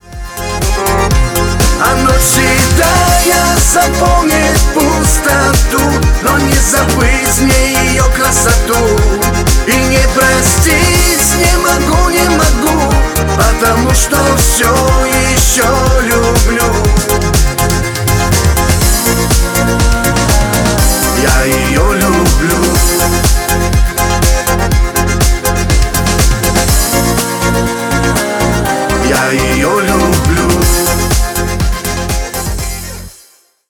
Шансон
кавказские